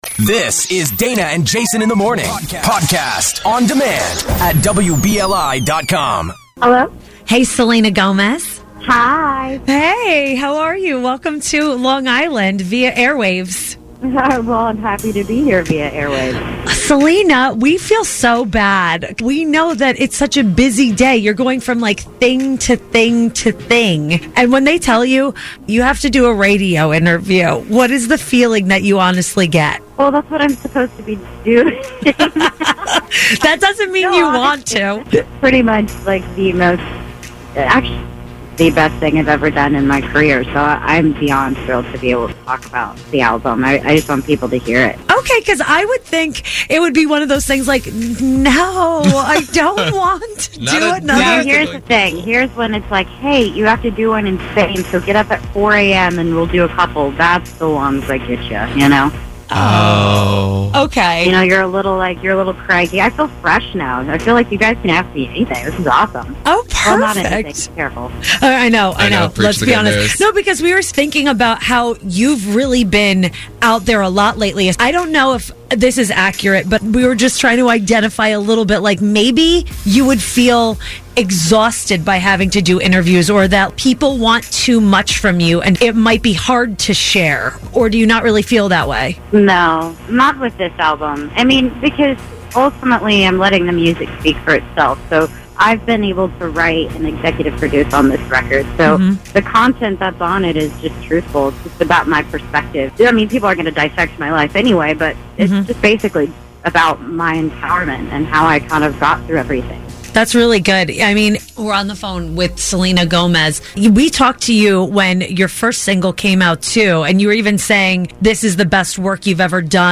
Selena’s interview with 106.1 BLI.